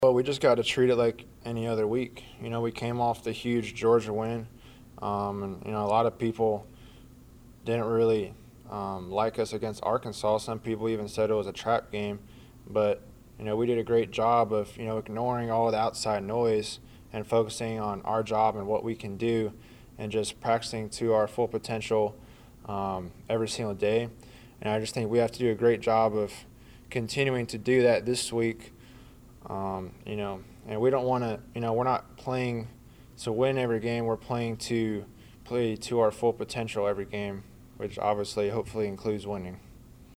Trask understands the task at hand and looks to take it one game at a time. Trask told the media about his focus Monday.